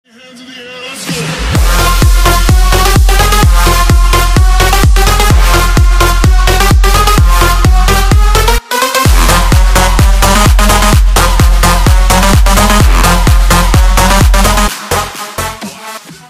Electronica_2.mp3